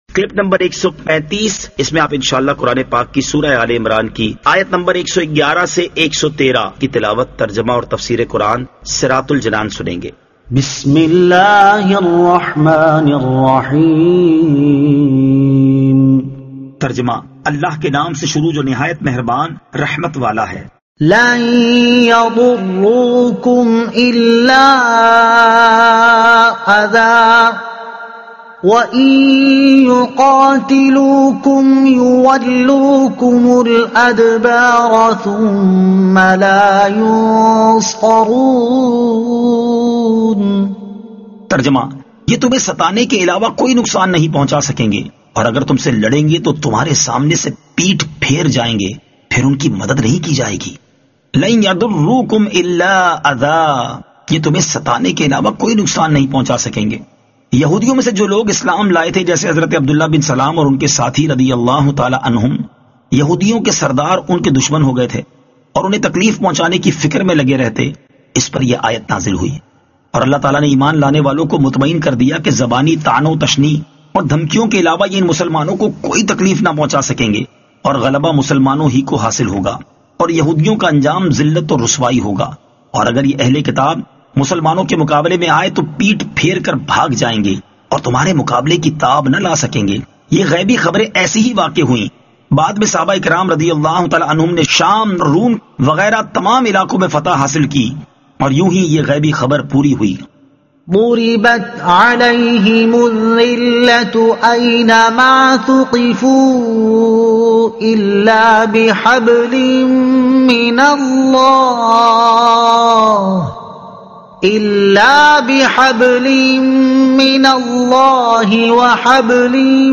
Surah Aal-e-Imran Ayat 111 To 113 Tilawat , Tarjuma , Tafseer